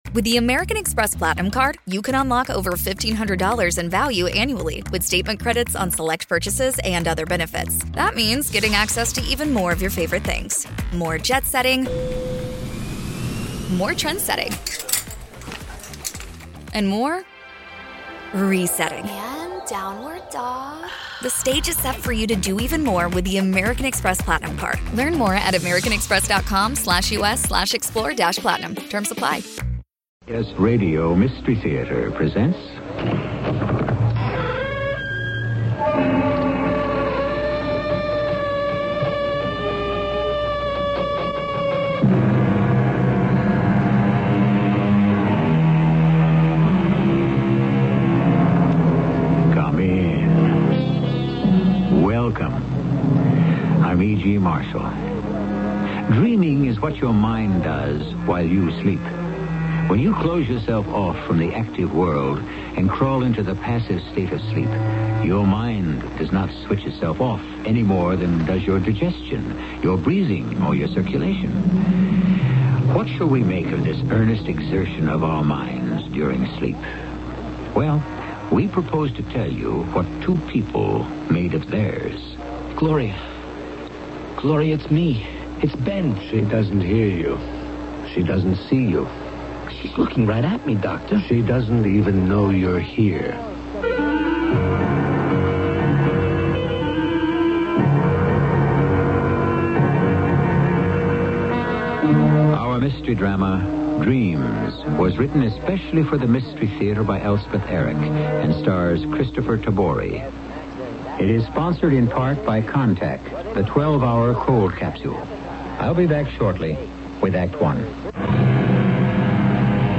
CBS Radio Mystery Theater (a.k.a. Radio Mystery Theater and Mystery Theater, sometimes abbreviated as CBSRMT) was a radio drama series created by Himan Brown that was broadcast on CBS Radio Network affiliates from 1974 to 1982